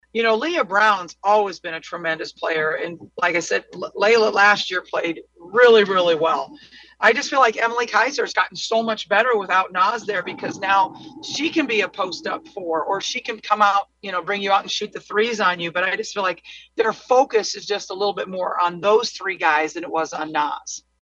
That’s Iowa coach Lisa Bluder who says a number of players have stepped up for Michigan.